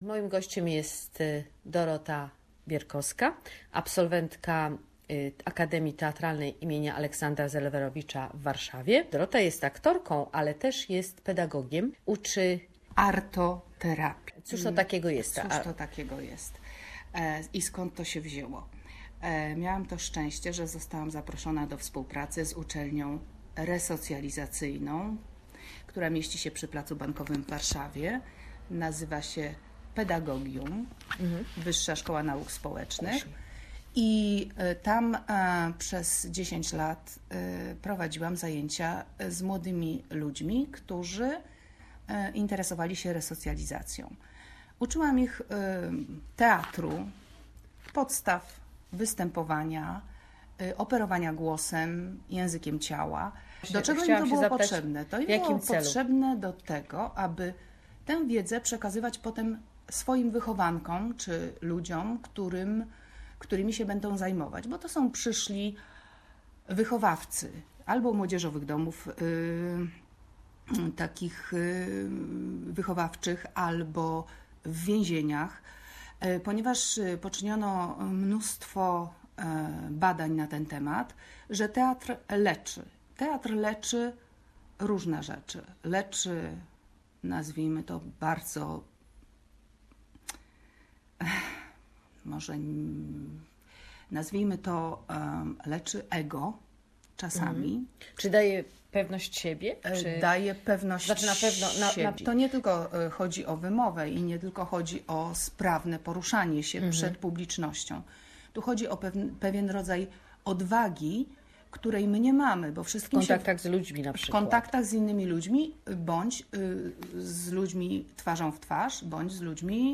An interview on the drama therapy. Its used in a wide variety of settings, from hospitals and schools to prisons.